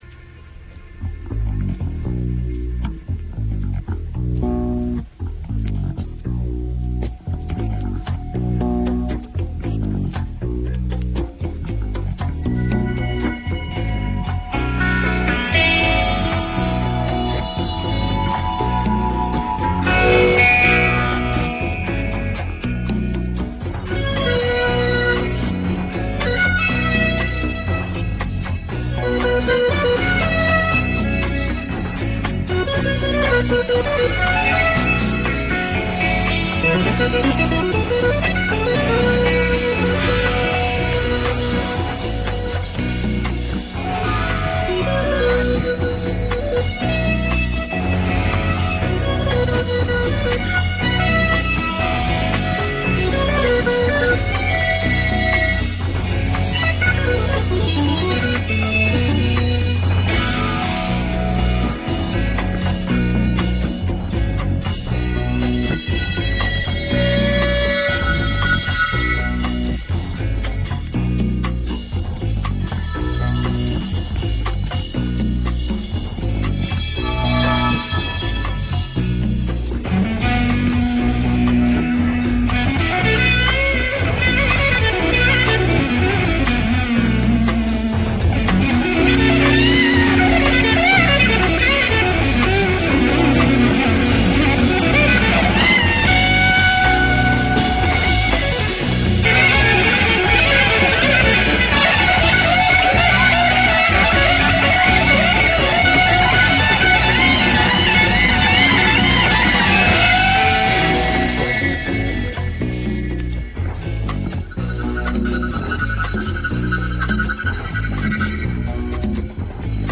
meditative